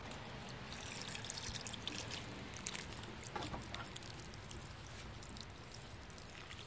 Descarga de Sonidos mp3 Gratis: regar plantas.
regadera-2-.mp3